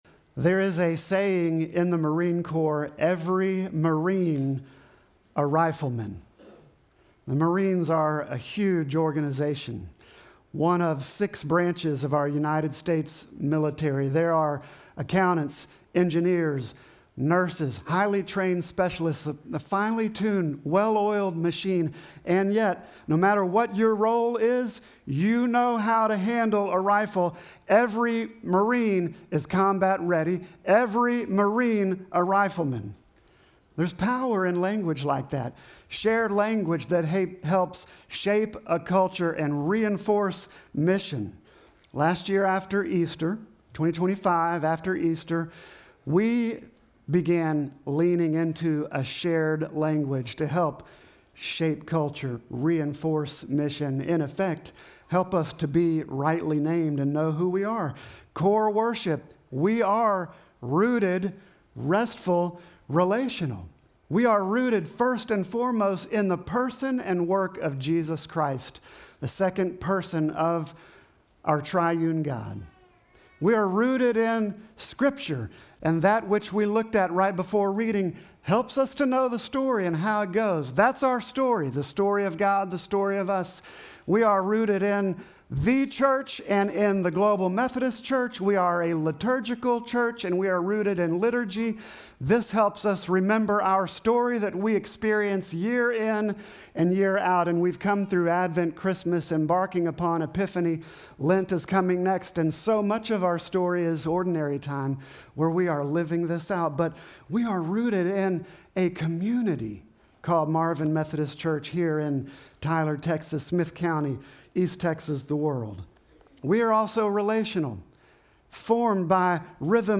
Sermon text: Ezekiel 47:1-12